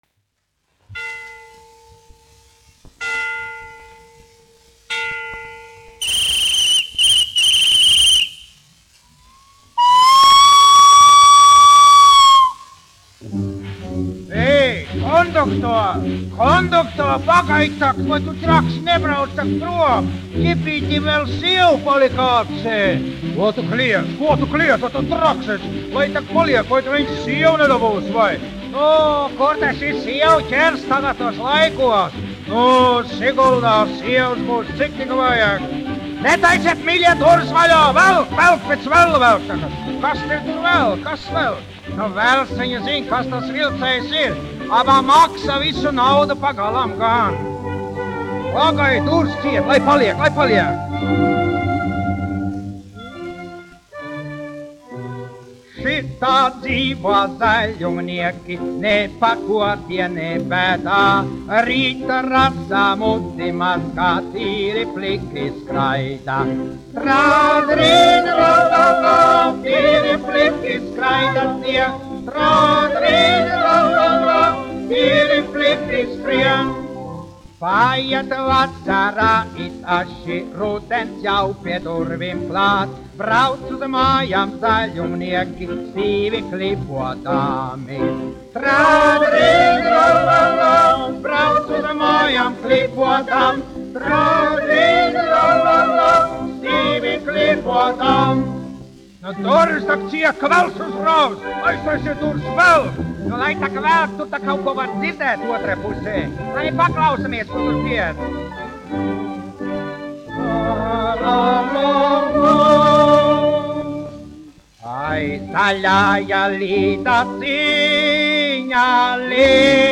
1 skpl. : analogs, 78 apgr/min, mono ; 25 cm
Humoristiskās dziesmas
Skaņuplate
Latvijas vēsturiskie šellaka skaņuplašu ieraksti (Kolekcija)